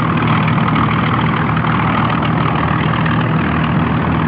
bombarder.mp3